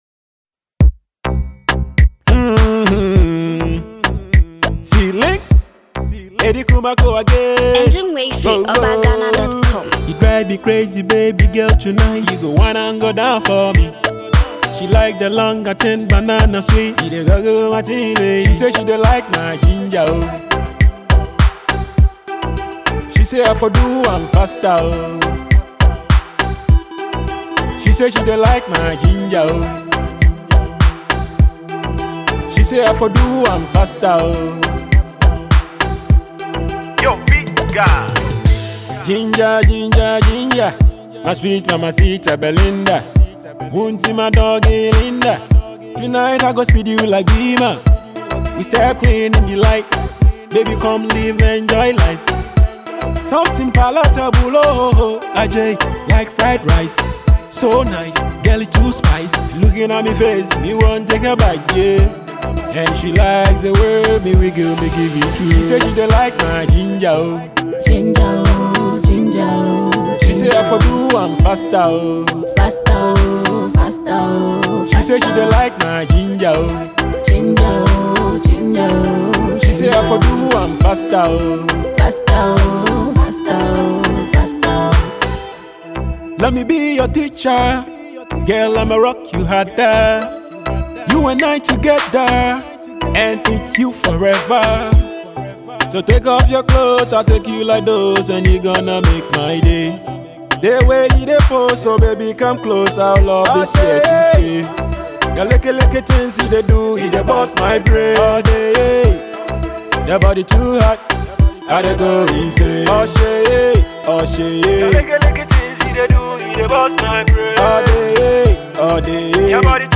afrobeat song